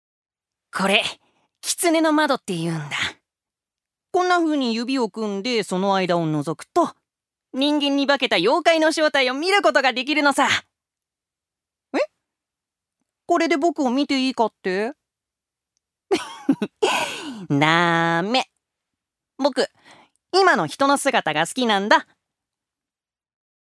所属：男性タレント
セリフ５